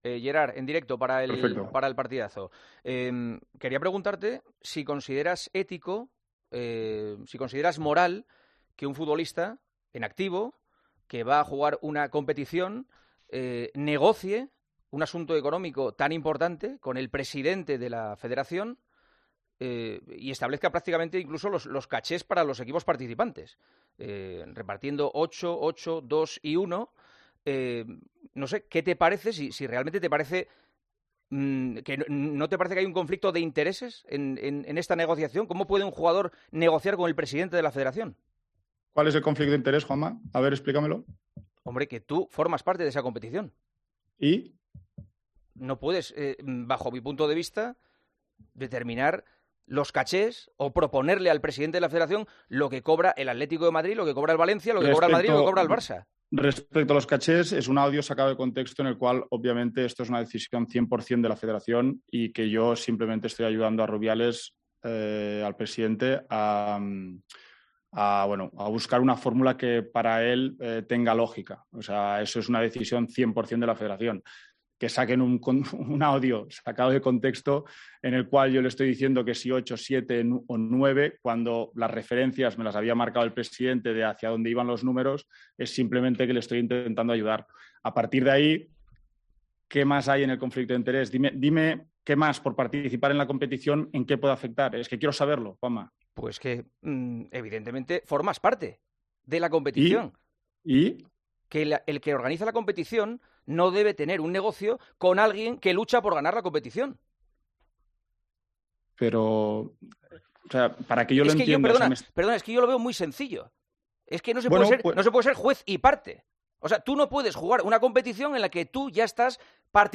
Todas estas informaciones las conocemos tras el enganchón que protagonizaron en directo Juanma Castaño y el propio Piqué en 'El Partidazo de COPE'.